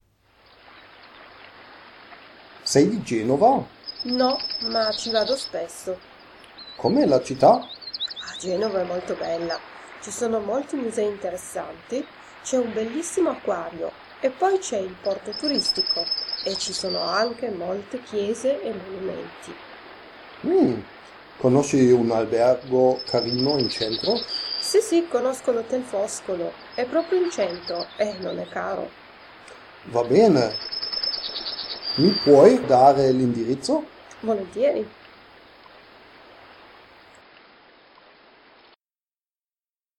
In jeder Übung sind Muttersprachler zu hören, die sich mit in einer Fremdsprache sprechenden Personen unterhalten.